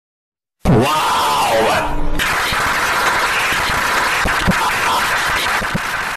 Memes
Wow Clap Meme